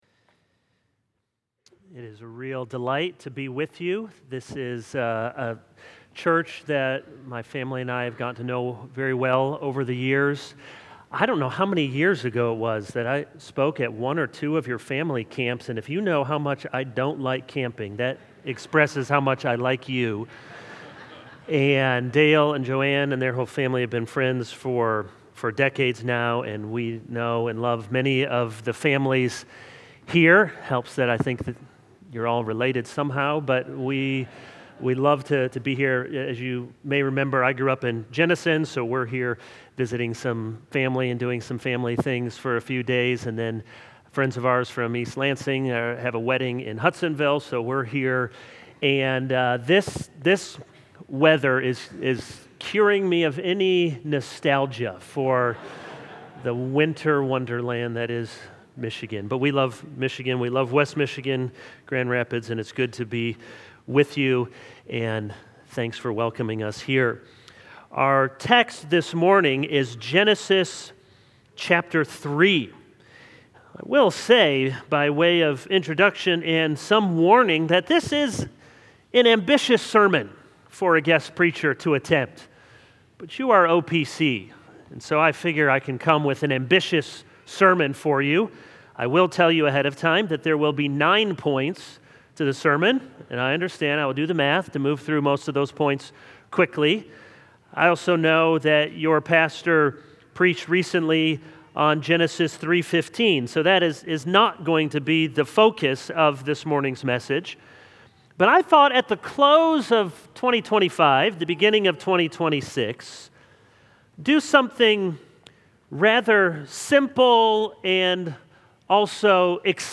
Harvest OPC Sermons